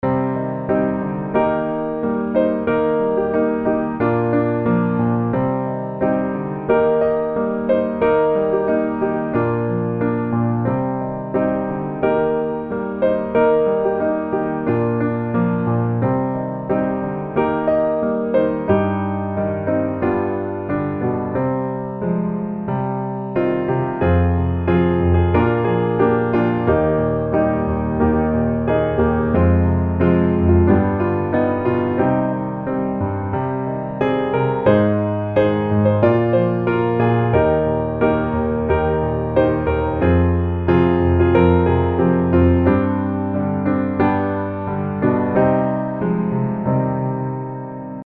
C Mineur